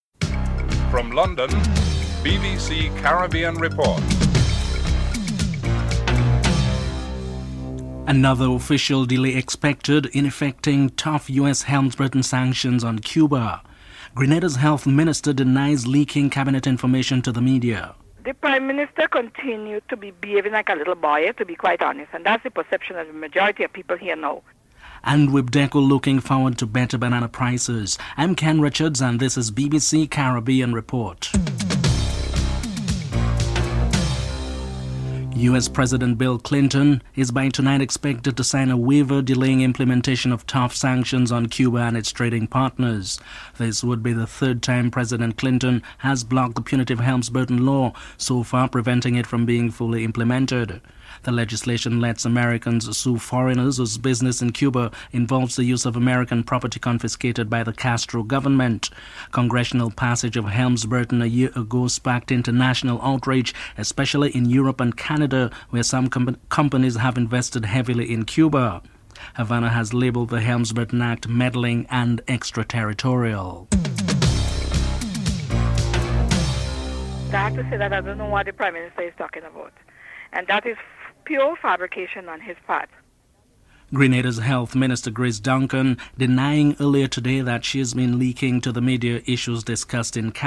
1. Headlines (00:00-00:34)
3. Grace Duncan, Grenada's Health Minister in an interview denies leaking cabinet information to the media.